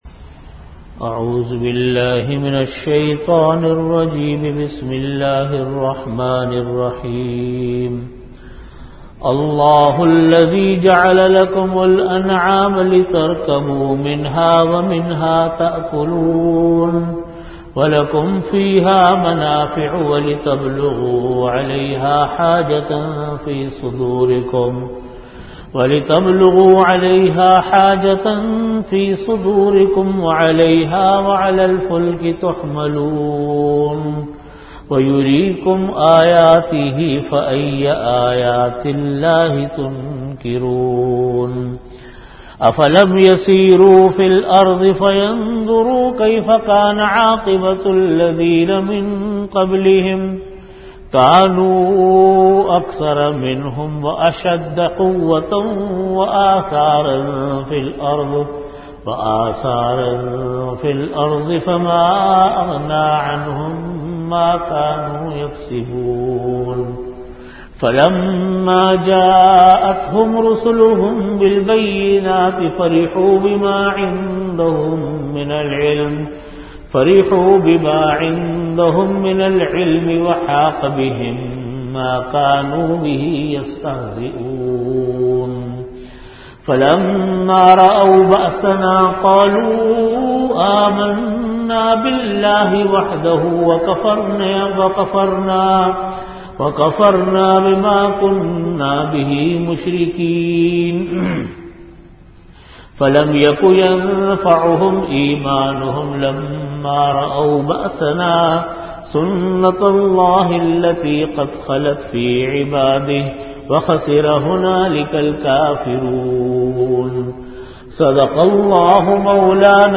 Audio Category: Tafseer
Time: After Asar Prayer Venue: Jamia Masjid Bait-ul-Mukkaram, Karachi